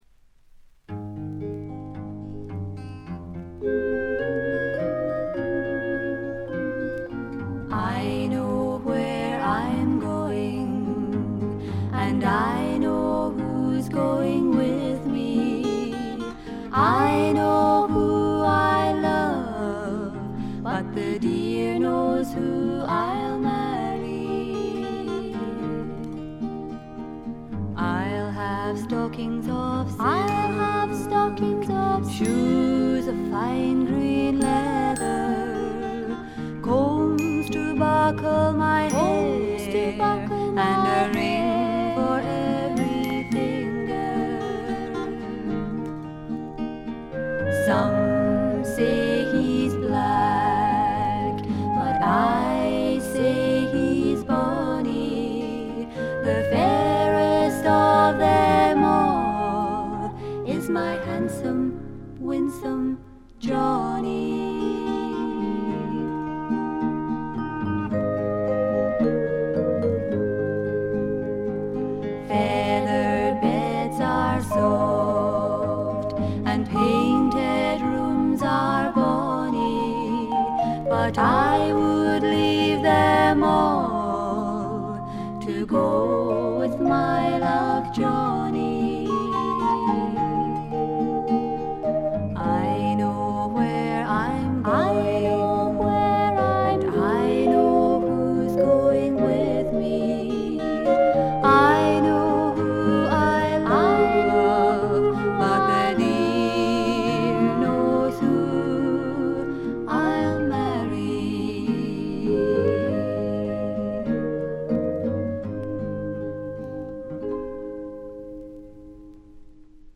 軽微なチリプチやバックグラウンドノイズ、散発的なプツ音少々。
内容はまさしく天使の歌声を純粋に楽しめる全14曲です。
アレンジはあくまでもフォークであってギターのアルペジオ主体の控え目なものです。
試聴曲は現品からの取り込み音源です。